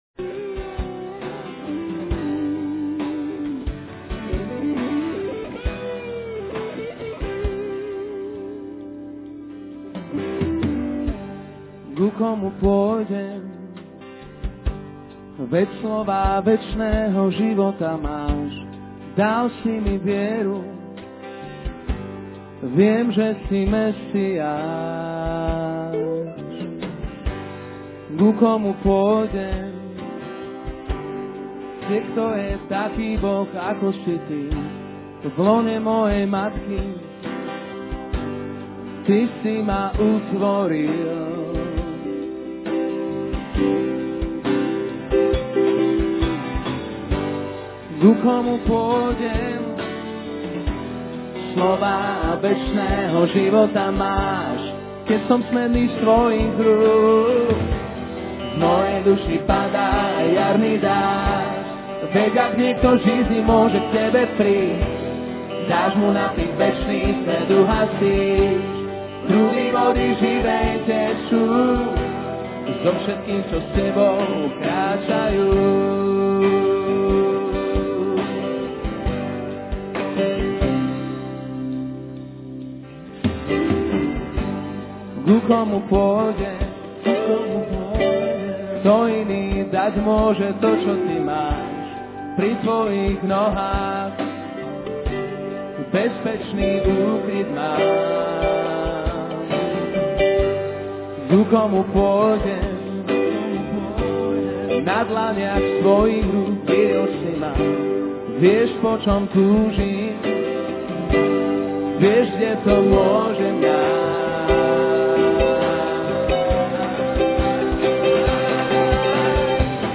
Being to Familar With Christ - Slovakia Conference 2003 (Slovakian/english) by David Wilkerson | SermonIndex